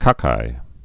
(kŏkī)